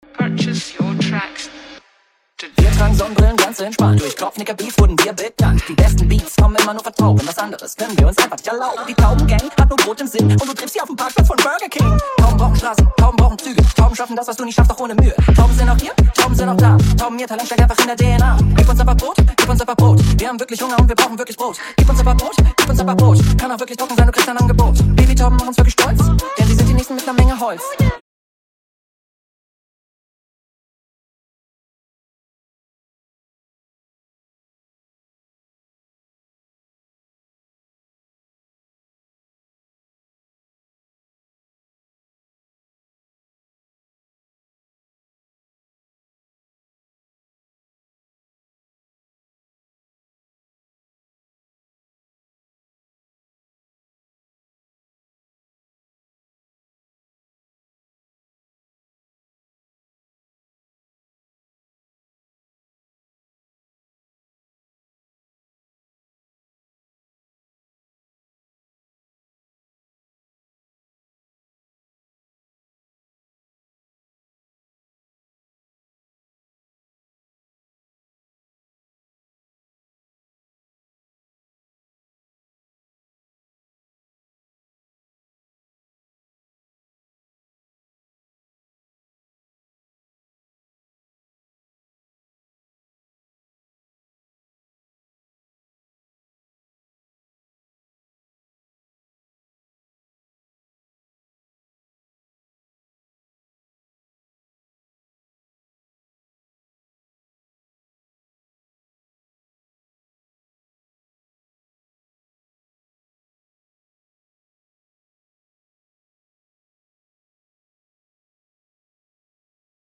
Ist das verschnellert? Klingt bissl komisch. Audio bricht auch ab
klingt verschnellert perfekt 2min nichts